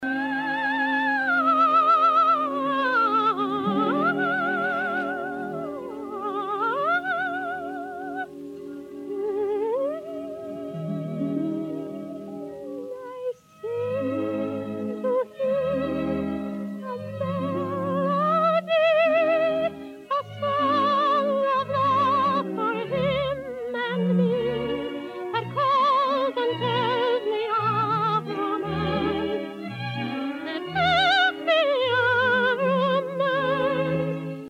soprano